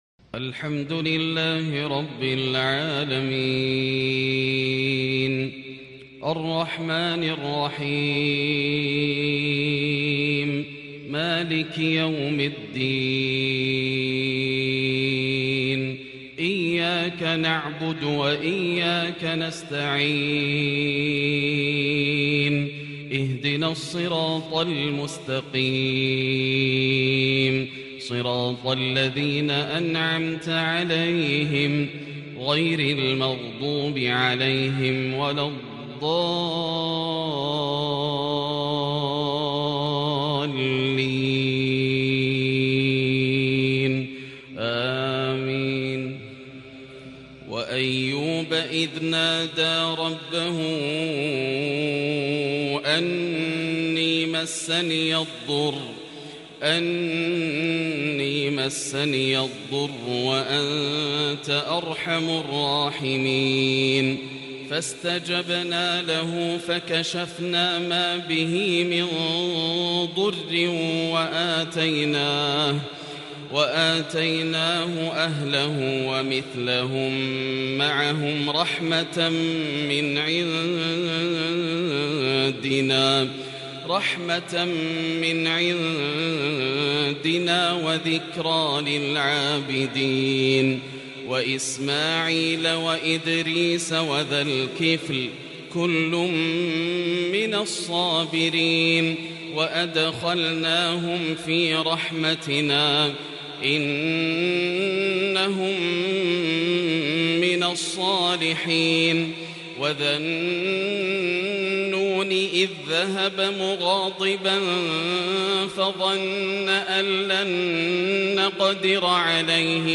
صلاة الفجر تلاوة من سورة الأنبياء الثلاثاء 19 صفر 1442هـ |  Fajr Prayar from Surah AlAnbiya | 6/10/2020 > 1442 🕋 > الفروض - تلاوات الحرمين